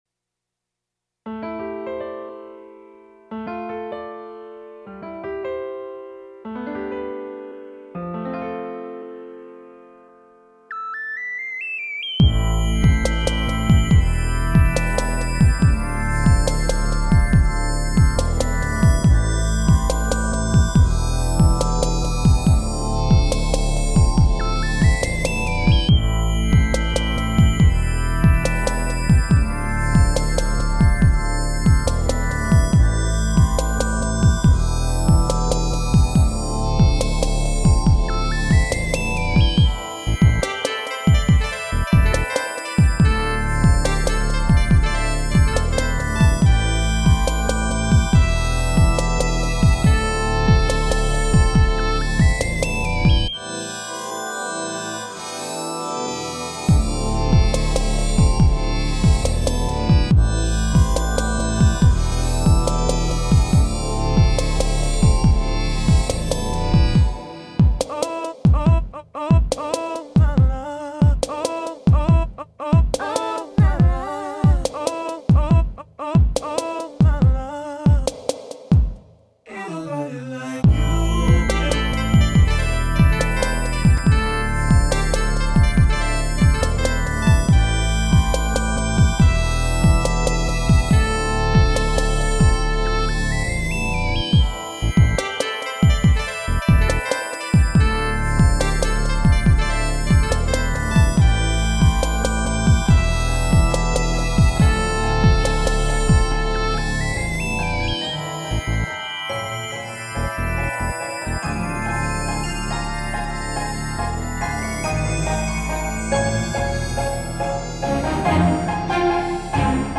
mix series
soul, electronic and future bass